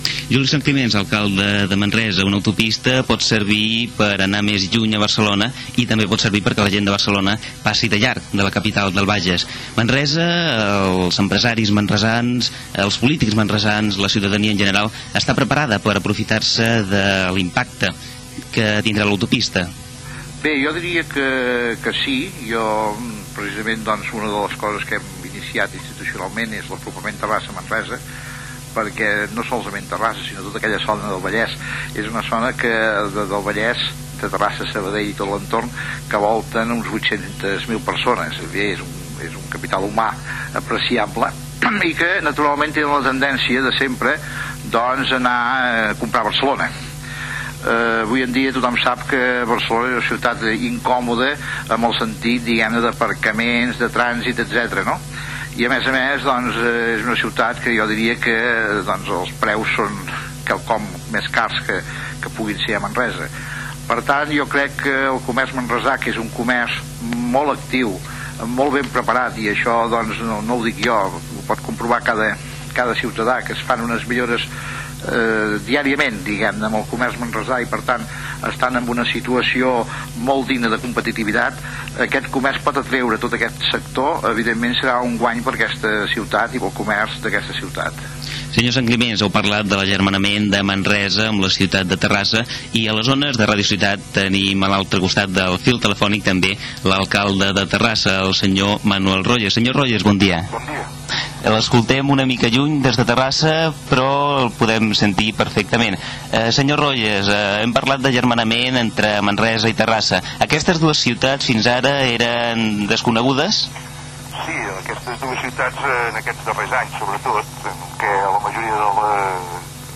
Inici de la programació especial amb motiu de la inauguració de l'Autopista Terrassa - Manresa. Entrevistes telefòniques amb els alcaldes de Manresa, Juli Sanclimens, i de Terrassa, Manuel Royes. Hora. Indicatiu de l'emissora.
Informatiu
FM